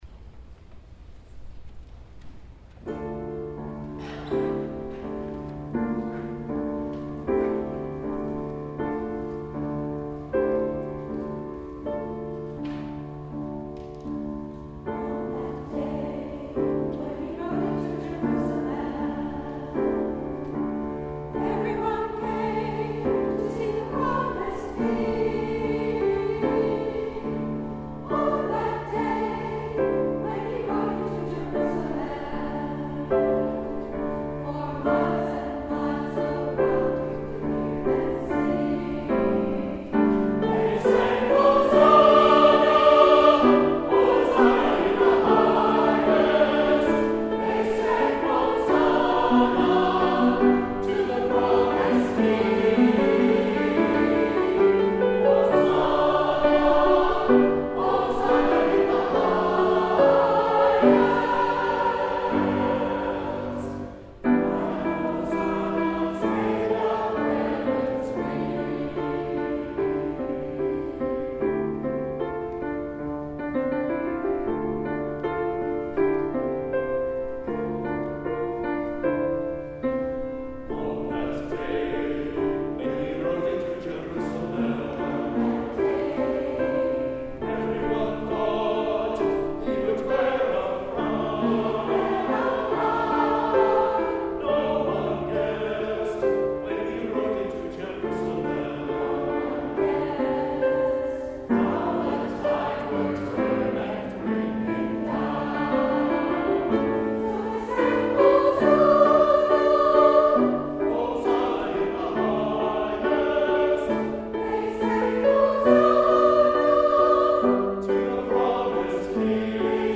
The St. William choir presented a Tenebrae Service on Palm Sunday 2015.
Track 2    - Choral piece "On That DayWhen He Rode Into Jerusalem